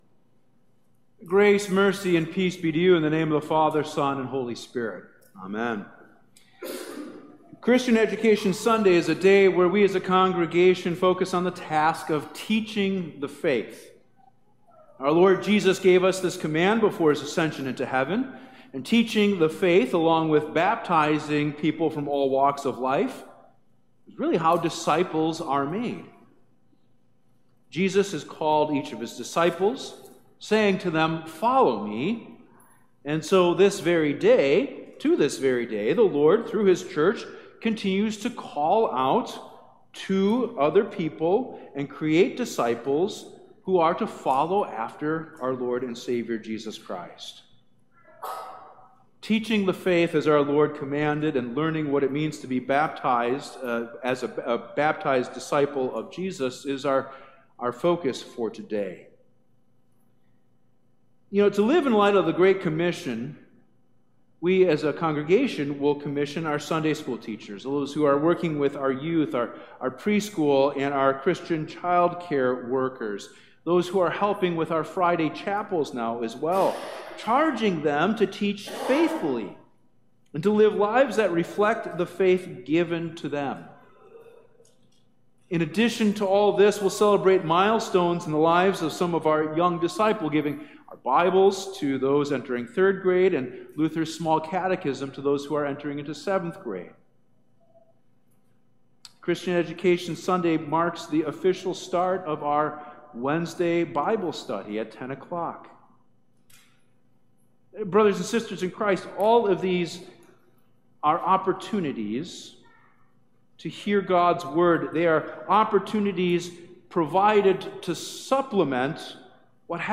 Sermons
2025 Sermons Hebrews 11:1-6 • The 9th Sunday after Pentecost – Christian Education Sunday http